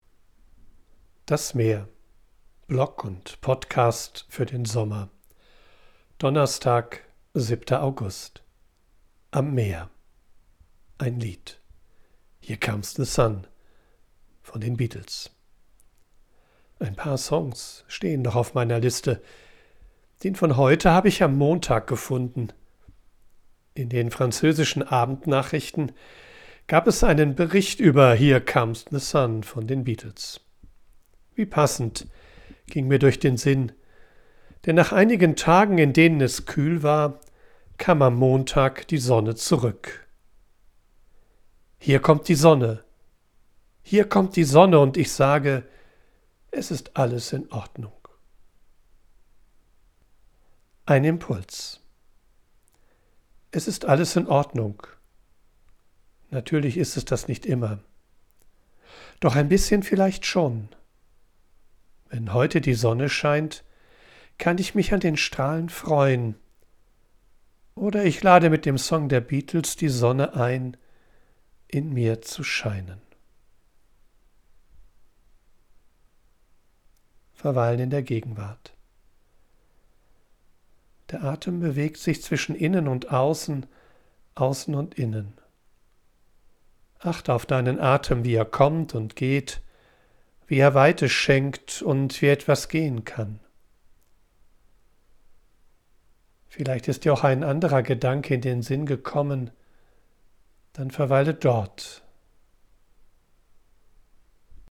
Ich bin am Meer und sammle Eindrücke und Ideen.
von unterwegs aufnehme, ist die Audioqualität begrenzt. Dafür
mischt sie mitunter eine echte Möwe und Meeresrauschen in die